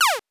8 bits Elements / laser shot
laser_shot_11.wav